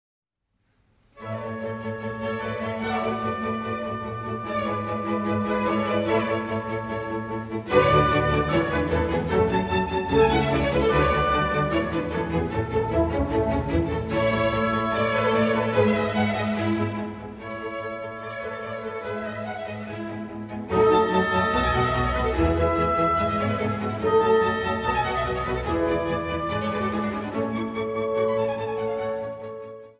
repräsentative Live-Aufnahmen